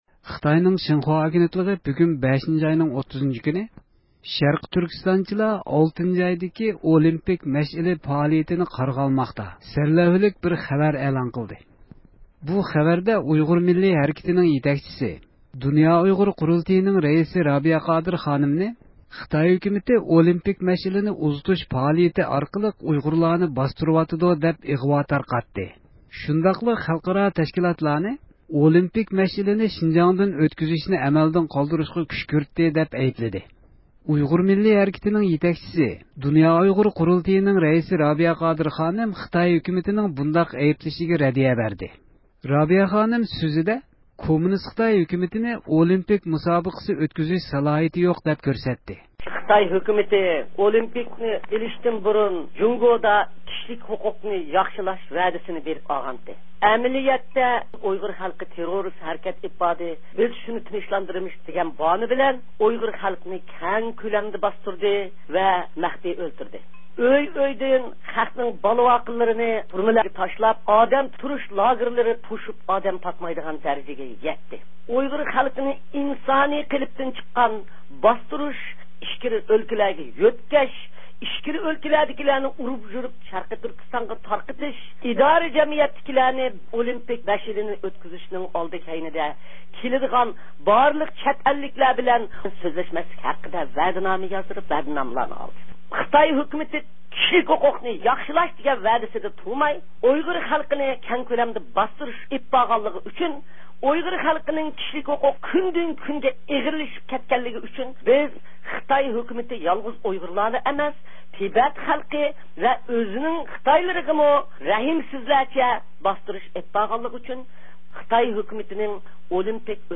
دولقۇن ئەيسا ئەپەندى خىتاي ھۆكۈمىتىنىڭ ئۇيغۇر مەدەنىيىتىنى يوق قىلىۋاتقانلىقىنىڭ پاكىتلىرىنى ئوتتۇرىغا قويۇپ، خىتاي ھۆكۈمىتىگە رەددىيە بەردى.